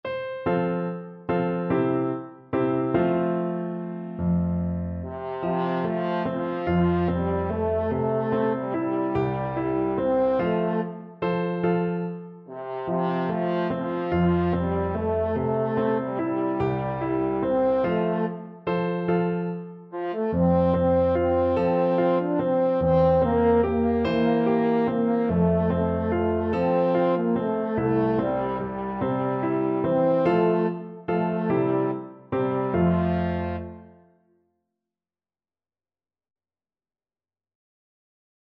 French Horn
Traditional Music of unknown author.
3/4 (View more 3/4 Music)
One in a bar =c.145
F major (Sounding Pitch) C major (French Horn in F) (View more F major Music for French Horn )